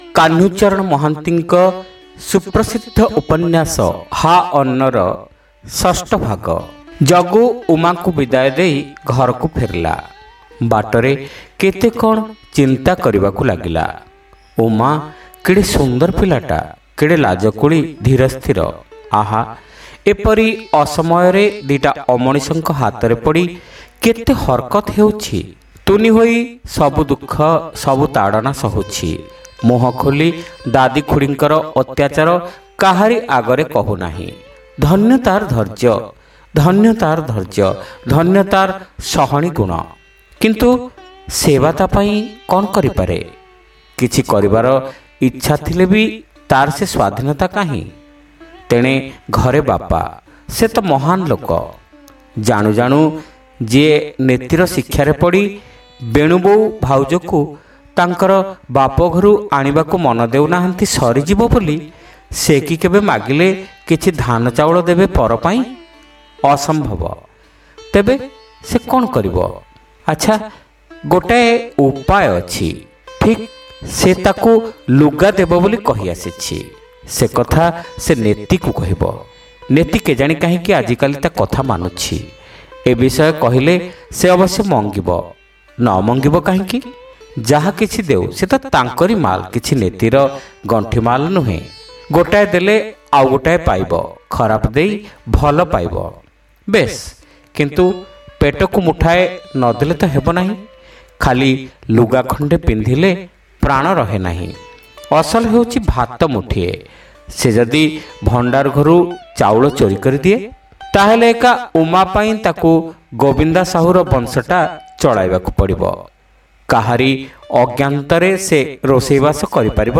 ଶ୍ରାବ୍ୟ ଉପନ୍ୟାସ : ହା ଅନ୍ନ (ଷଷ୍ଠ ଭାଗ)